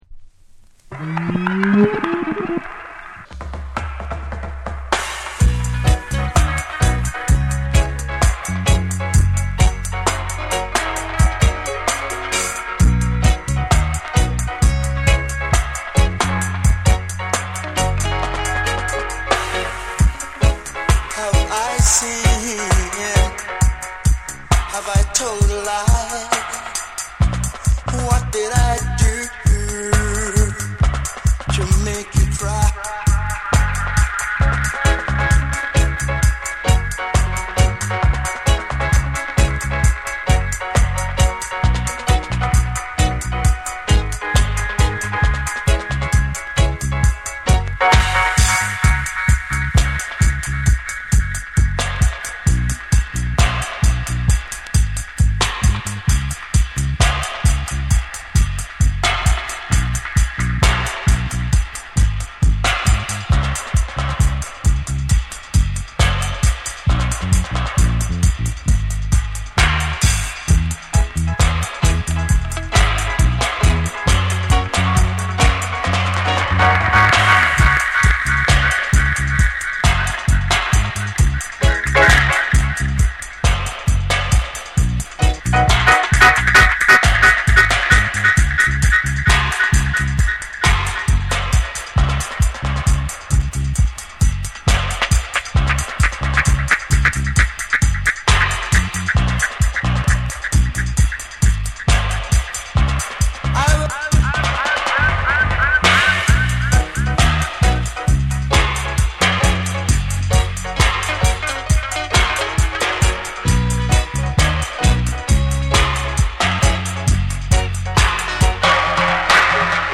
ヘヴィなステッパーズ・リズムを軸に、深く沈み込むベースとエコー／リバーブを効かせた空間的なミックスが際立つダブを収録。
REGGAE & DUB